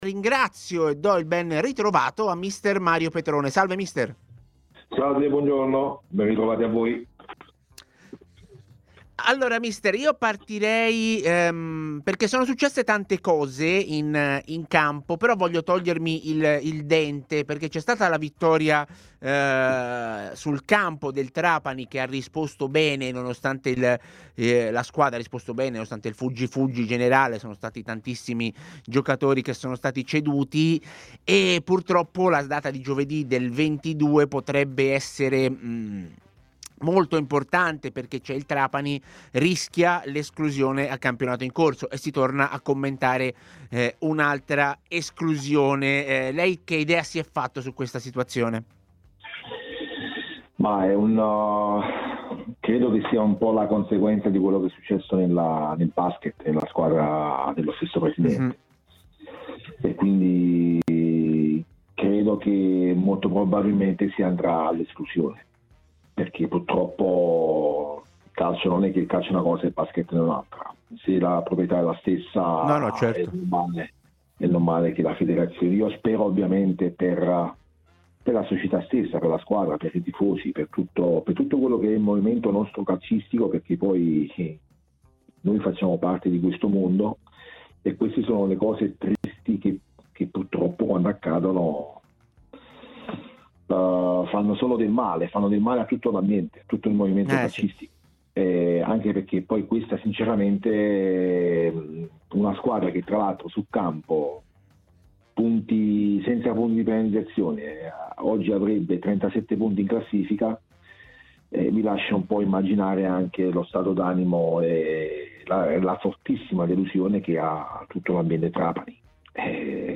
'A Tutta C', trasmissione in onda su TMW Radio e iL61